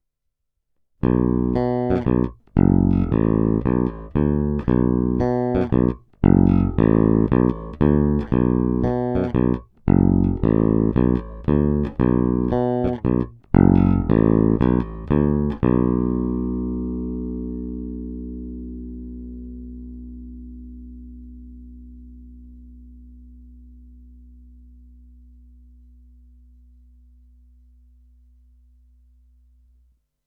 Zvukově je to naprosto klasický a opravdu skvělý Jazz Bass.
Není-li uvedeno jinak, následující nahrávky jsou provedeny rovnou do zvukové karty a bez stažené tónové clony.
Snímač u kobylky